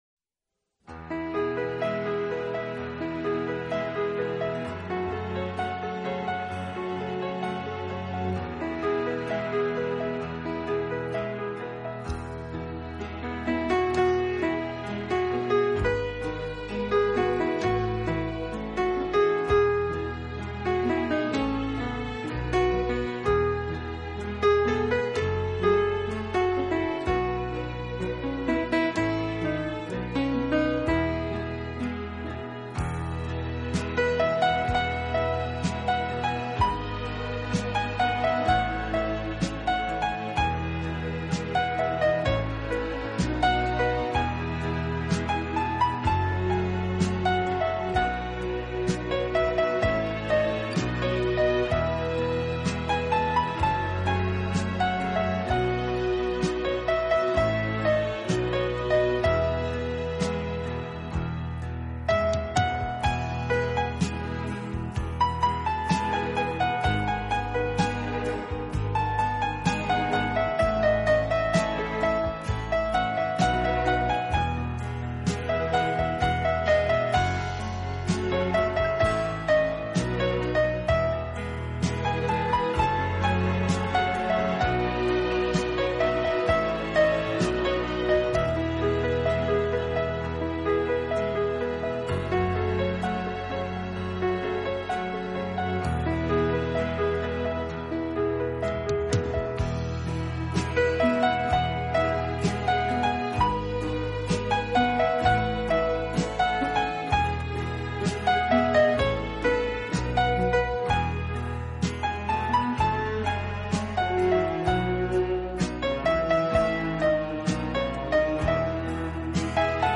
【浪漫钢琴】
钢琴演奏版，更能烘托出复古情怀，欧美钢琴大师深具质感的演奏功力，弹指
本套CD全部钢琴演奏，